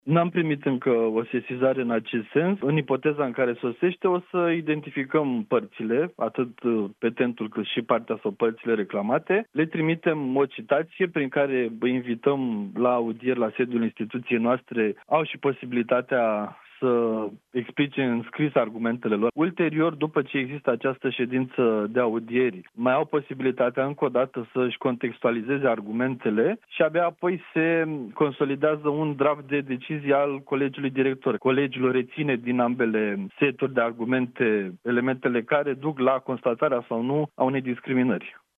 Cătălin Raiu, vicepreședinte CNCD: „Nu am primit încă o sesizare în acest sens”